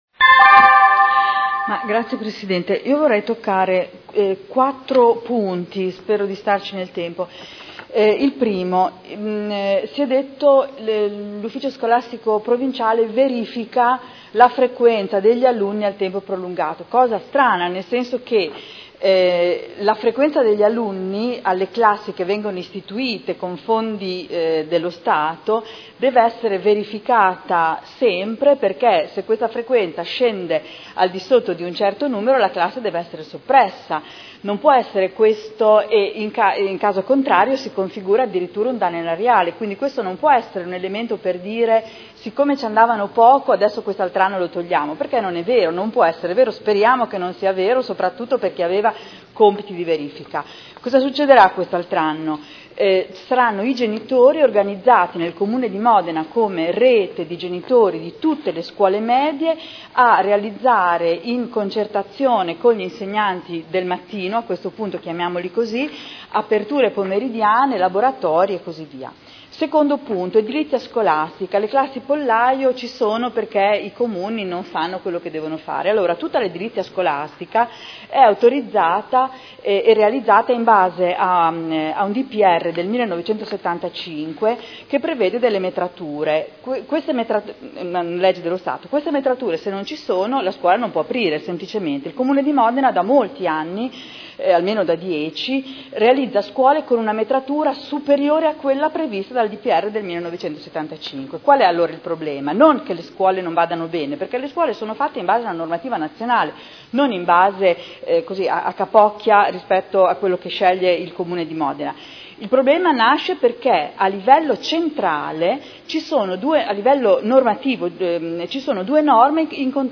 Adriana Querzè — Sito Audio Consiglio Comunale
Seduta del 18/07/2011.